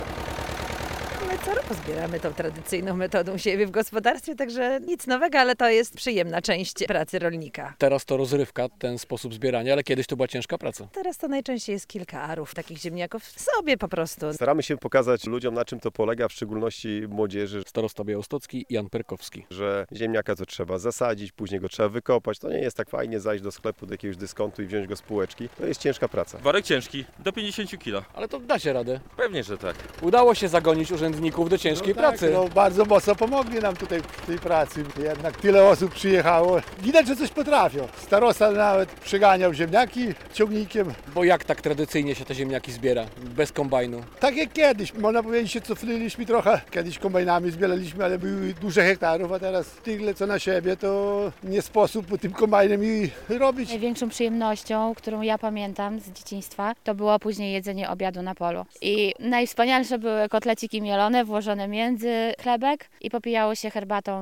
Wykopki powiatowe w miejscowości Targonie Wielkie - relacja